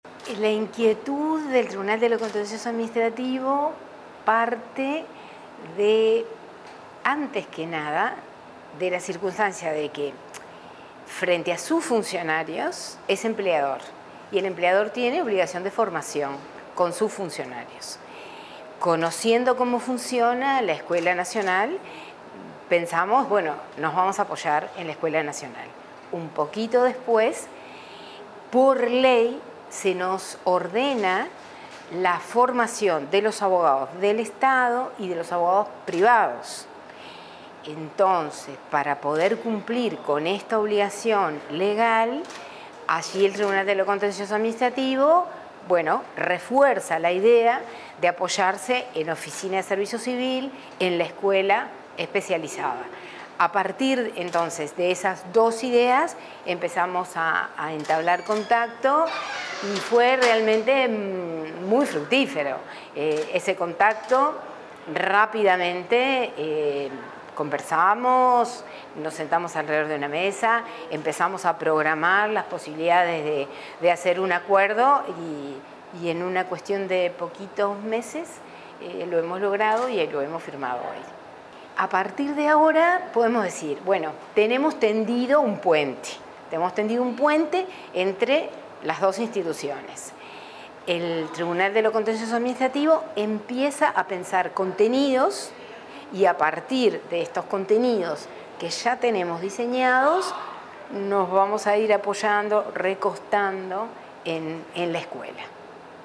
Declaraciones de la presidenta del TCA, Rosina Rossi